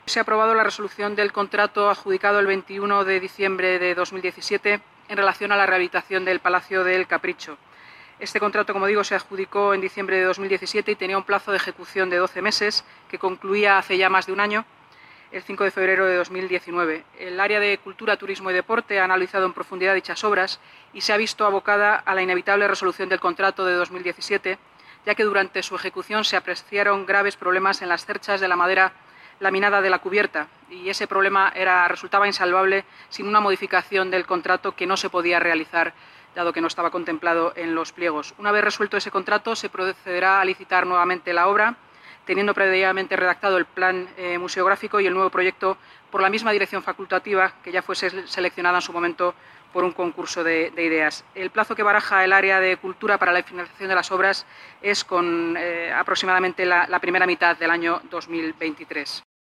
Nueva ventana:Declaraciones Inmaculada Sanz sobre rehabilitación Palacio El Capricho